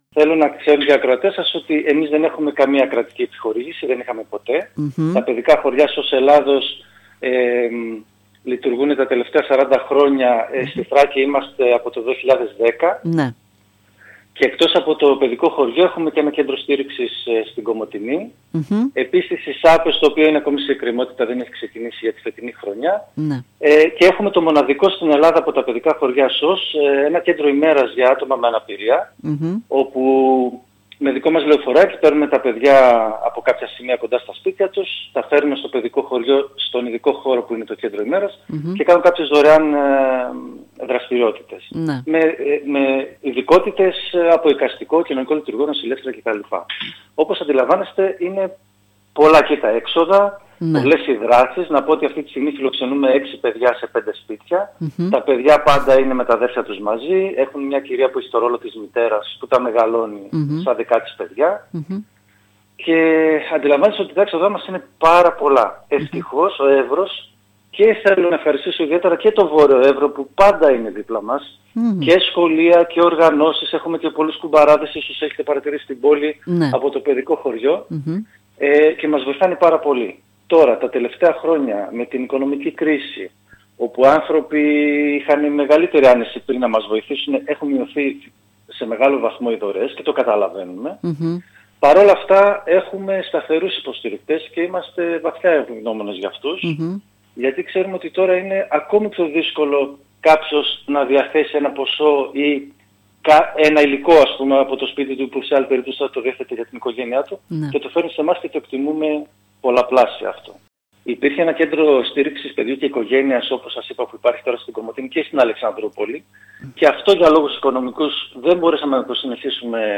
Τα προβλήματα επιβίωσης του  παιδικού χωριού SOS Θράκης στο Αρίστεινο,  παρουσίασε μιλώντας  στην ΕΡΤ Ορεστιάδας